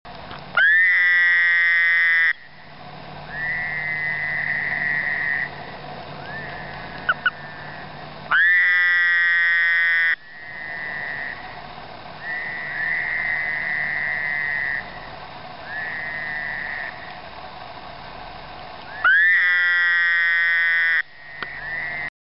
This is Bufo fowleri, and this will be quite startling in mixed company! :-)